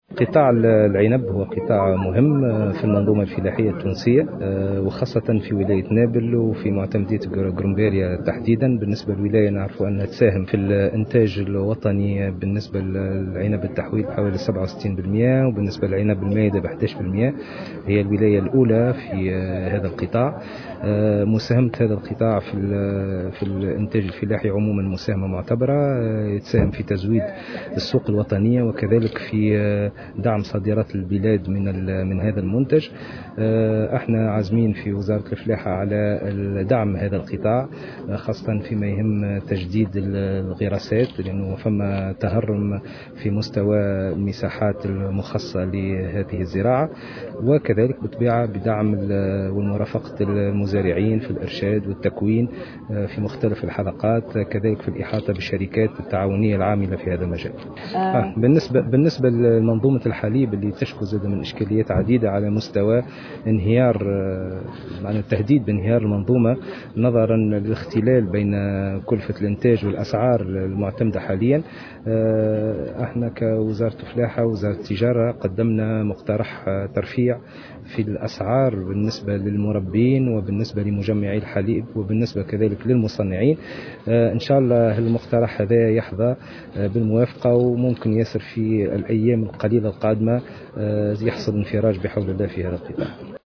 أكد وزير الفلاحة والموارد المائية والصيد البحري، أسامة الخريجي، على هامش افتتاح مهرجان العنب بقرمبالية، اليوم الخميس، أن الوزارة ستقترح في الأيام القادمة الترفيع في أسعار الحليب بالنسبة للمربين وللمجمعين والمصنعين، وذلك في ظل ما تشكوه المنظومة من اختلال بين كلفة الانتاج والاسعار المعتمدة حاليا.
وعبّر الخريجي في تصريح لمراسلة الجوهرة أف أم، عن أمله في أن يحظى المقترح الذي ستقدمه الوزارة بالموافقة، بما من شأنه أن يساهم في انفراج الازمة.